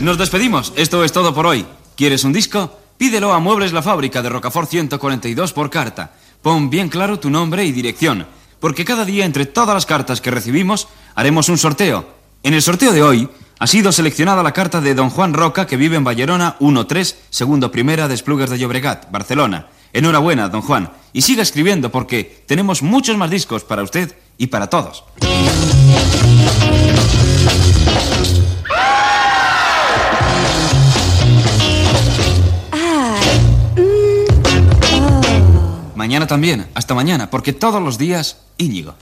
Tema musical, publicitat i presentació d'una novetat musical.